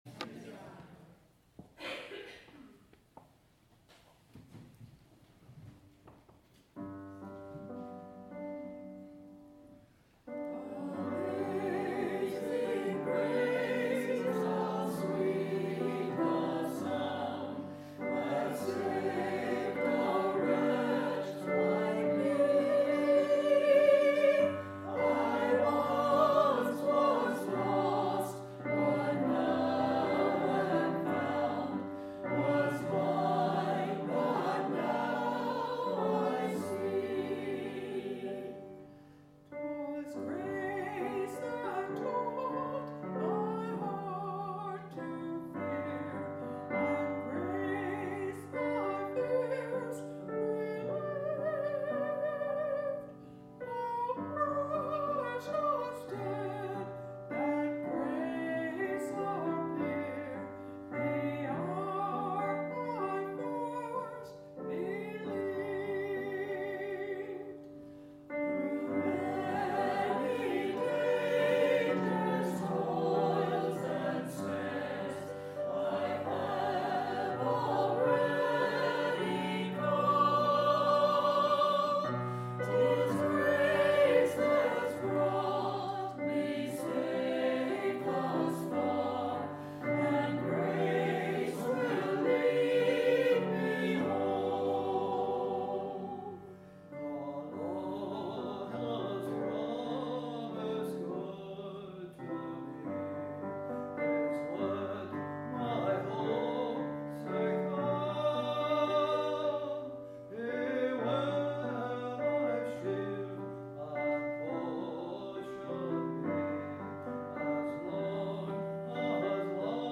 Sermon – A Day Off from the Day Off